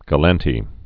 (gə-lăntē)